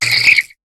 Cri de Posipi dans Pokémon HOME.